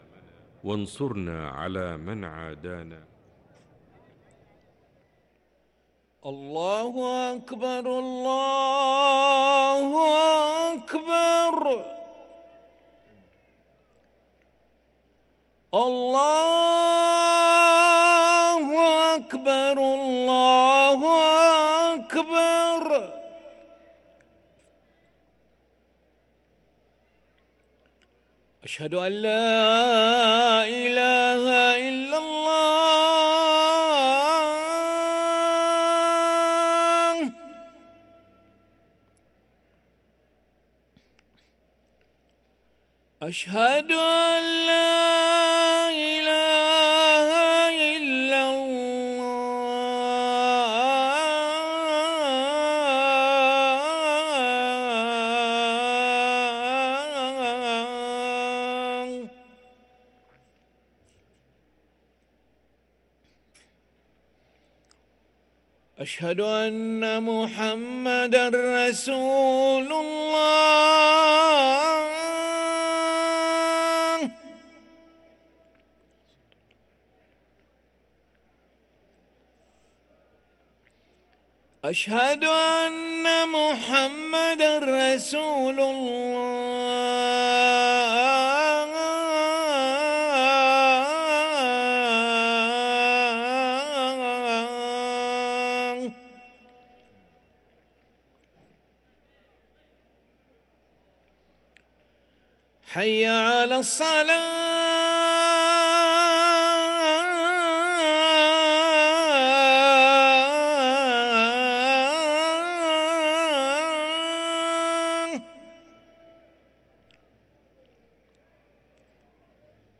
أذان العشاء للمؤذن علي أحمد ملا الأحد 24 شوال 1444هـ > ١٤٤٤ 🕋 > ركن الأذان 🕋 > المزيد - تلاوات الحرمين